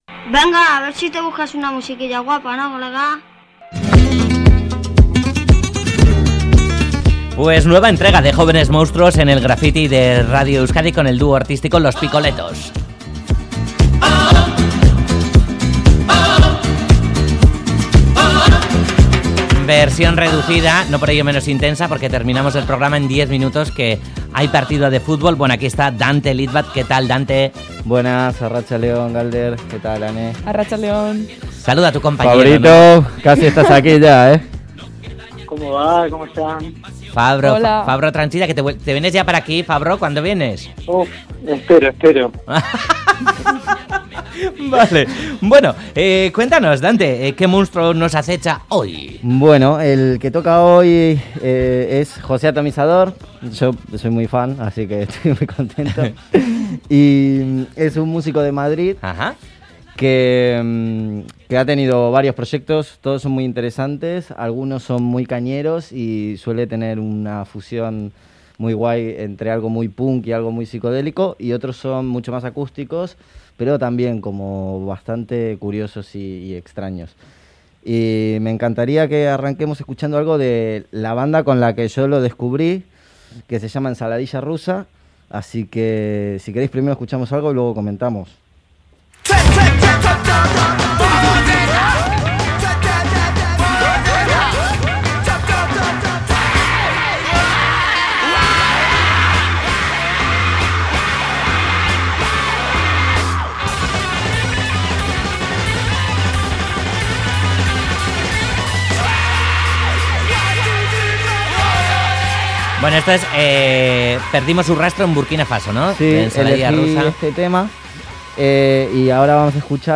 Viajamos a otra galaxia por los sonidos punk psicodélicos